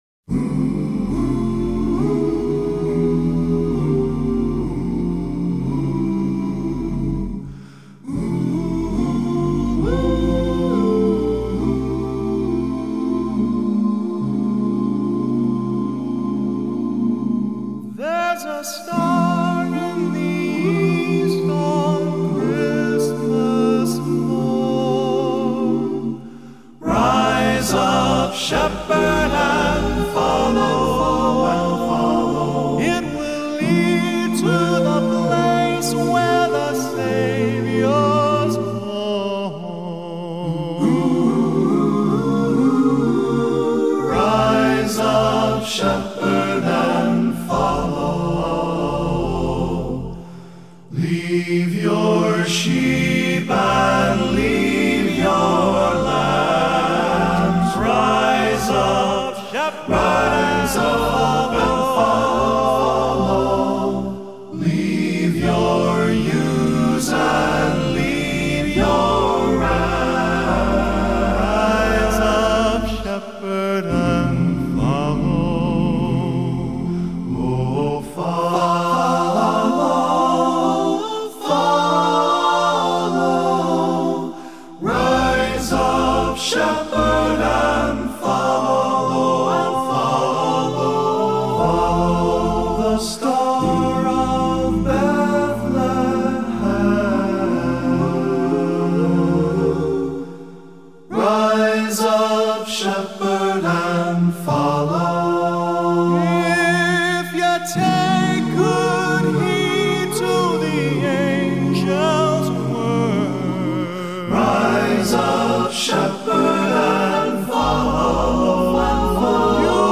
This Christmas song is interesting, it combines the shepherds, who came because they were told by the angels, with the Star, which is what brought the Magi. But I like the haunting, calling quality of it.